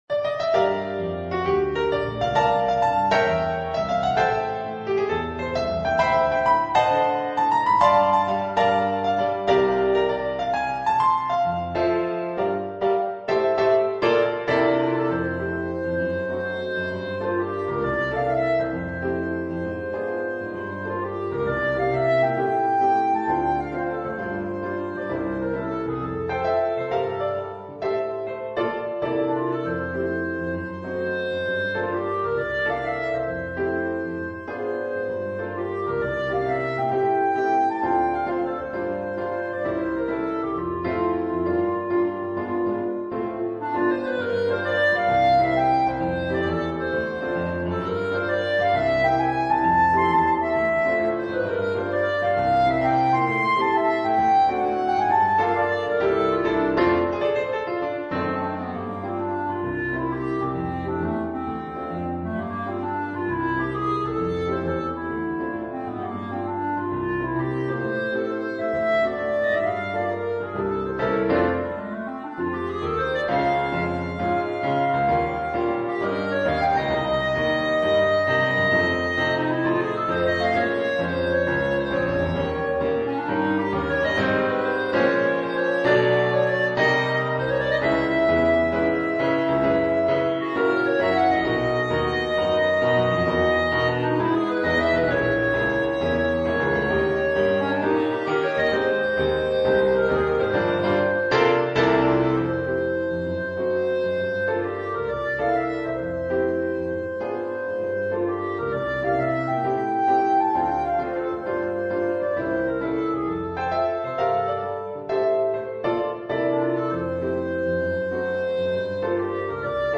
for clarinet and piano